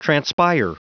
Prononciation du mot transpire en anglais (fichier audio)